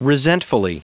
Prononciation du mot resentfully en anglais (fichier audio)
Prononciation du mot : resentfully